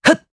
Esker-Vox_Attack1_jp.wav